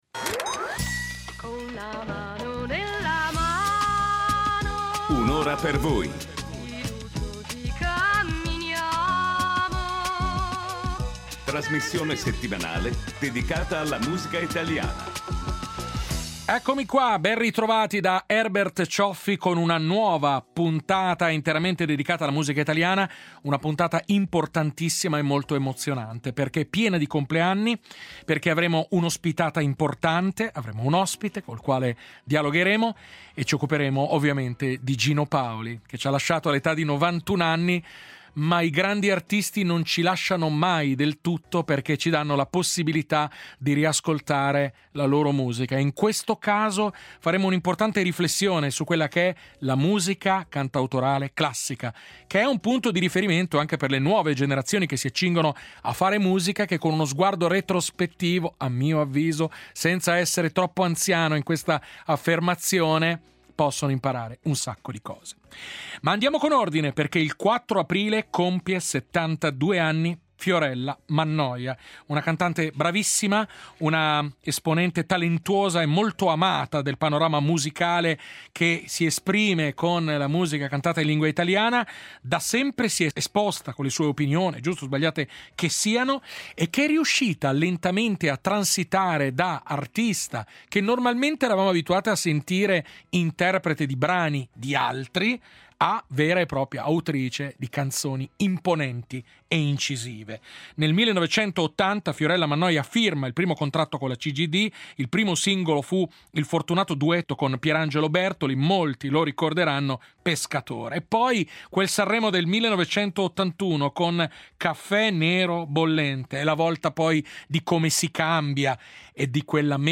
L’intervista a Cristiano De Andrè in tour con “De Andrè canta De Andrè”, musicista di alto livello, che riarrangia i capolavori di uno dei più grandi poeti del ‘900.